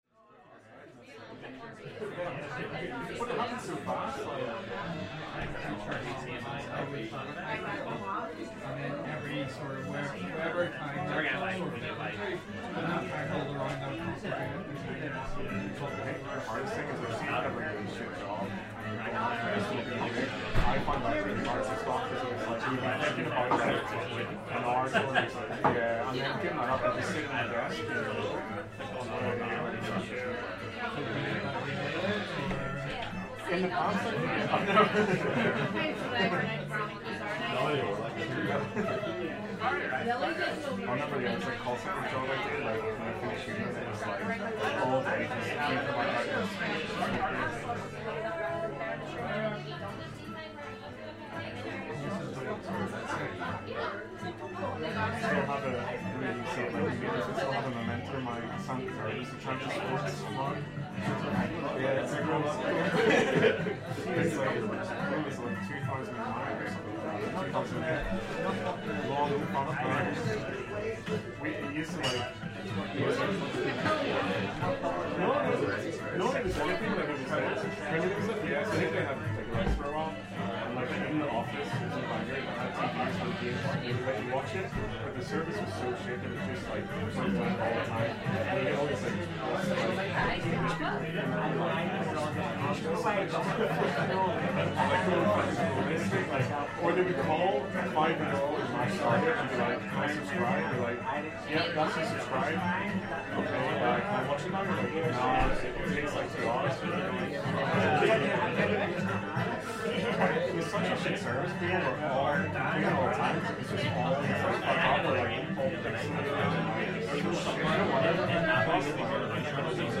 Spending the night in a traditional Irish pub
Ambience from inside Kelly's Cellars, a traditional Irish pub in Belfast, including traditional music, pub chatter and the sounds of drinking and general merriment.